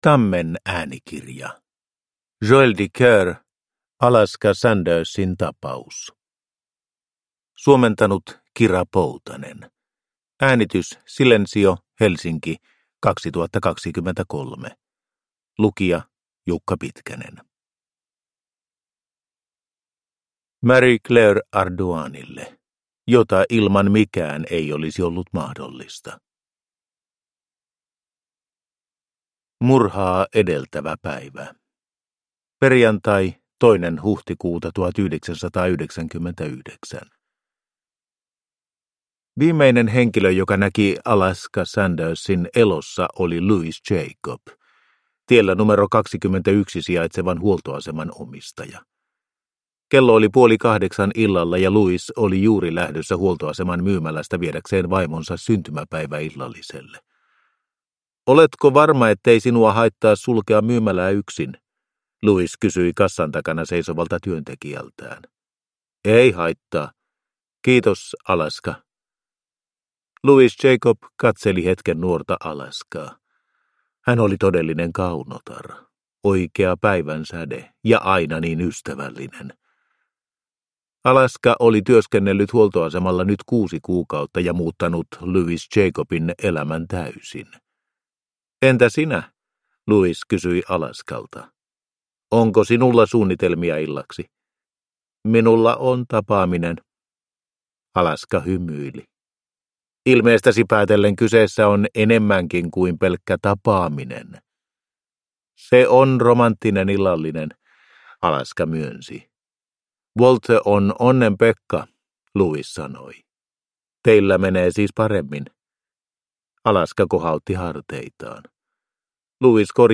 Alaska Sandersin tapaus – Ljudbok – Laddas ner